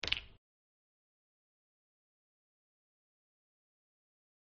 Крышку от сока отодвинули в сторону